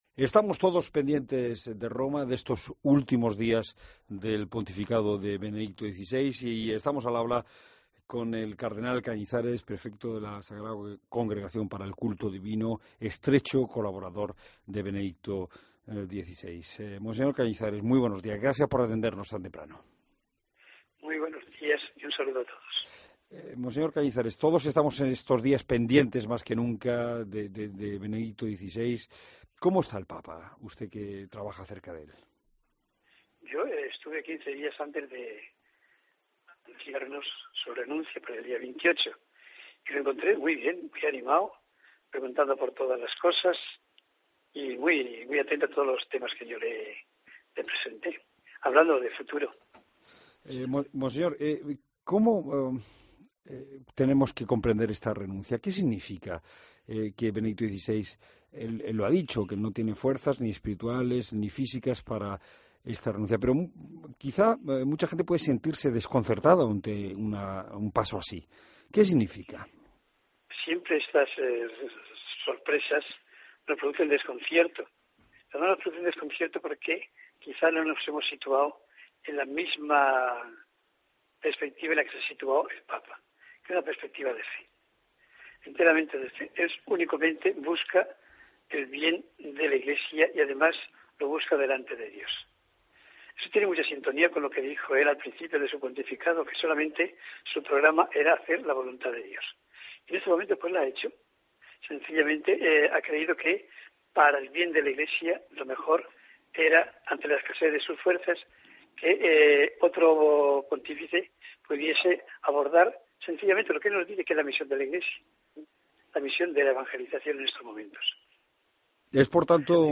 AUDIO: Escucha la entrevista a Monseñor Antonio Cañizares en COPE